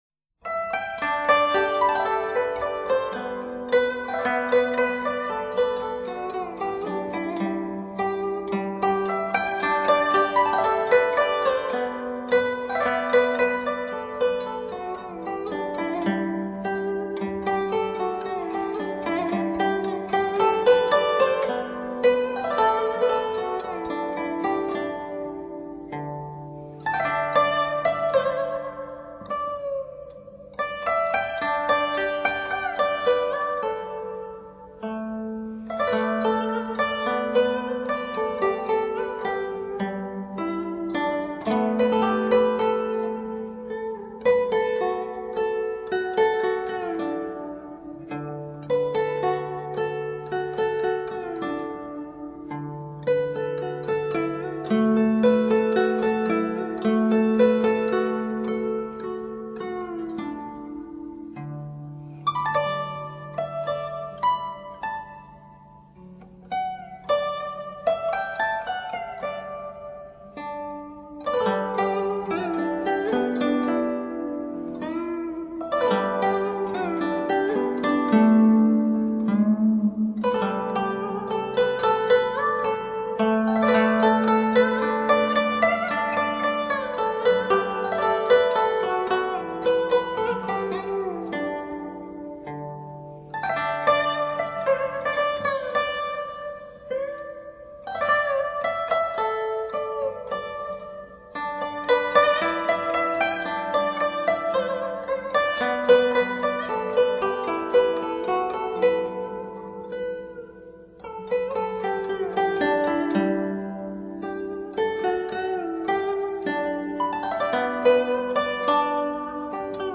当中国传统器乐遇上美国屹立百年的老教堂，将回荡出如何迷人的风情？
筝